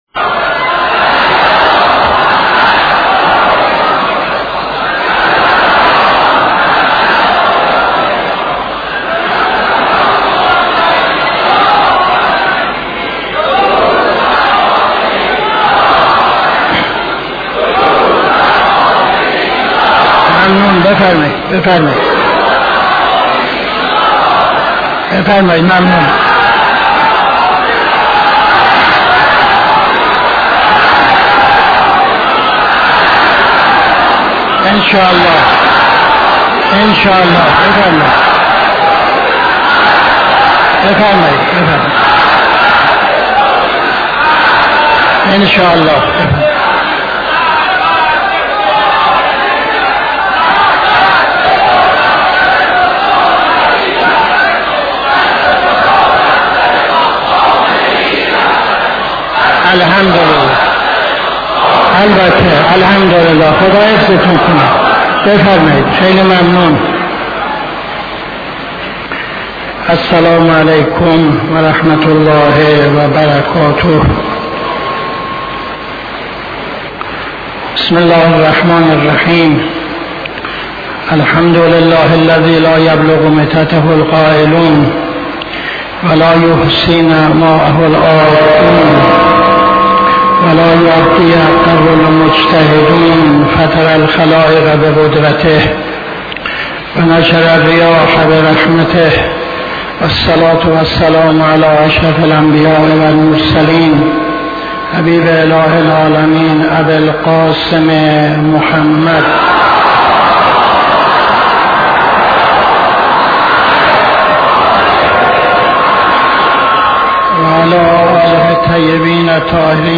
خطبه اول نماز جمعه 15-05-78